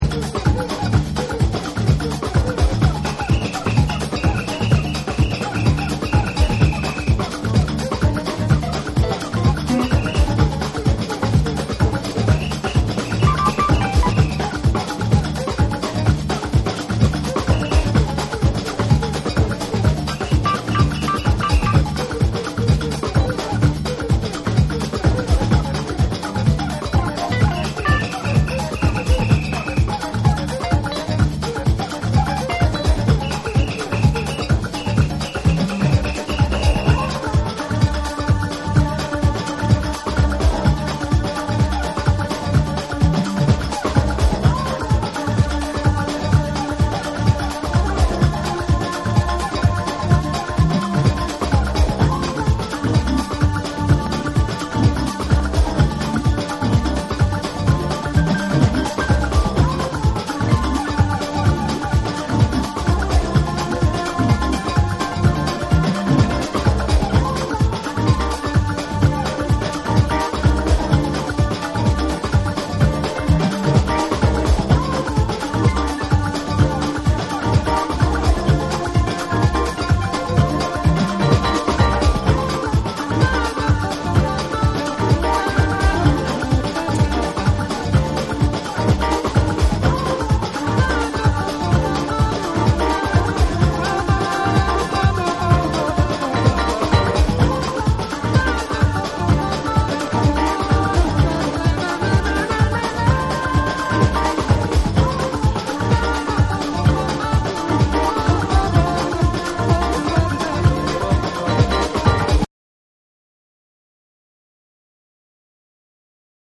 パーカッションを交えたラテンのリズムに、軽快なピアノやスキャットが絡むフロア・キラーなジャジー・ブレイク！
BREAKBEATS / ORGANIC GROOVE